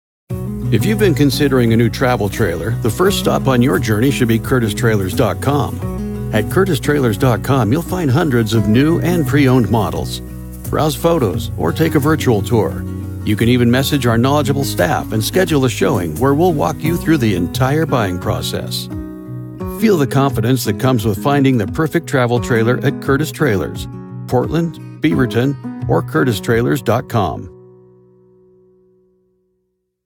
• Authentic and relatable — a real human presence, not a polished announcer veneer
• Gritty and grounded — forged in the mountains, with a modern Western edge
• Warm and trustworthy — the voice of a guide, not a salesman
Commercial Demo
Curtis-Trailers-Demo.mp3